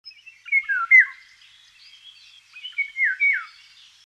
Nicht nur dass er hübsch aussieht, er singt auch ausgezeichnet. Wenn man im Frühjahr hier durch die Wälder streift hört man seinen sehr markanten Gesang immer und immer wieder.